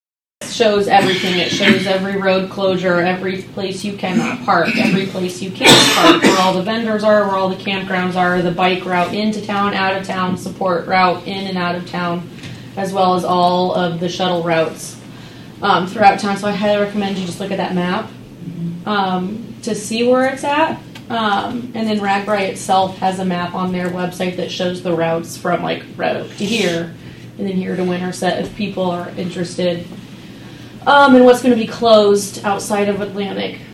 speaking at the Atlantic City Council meeting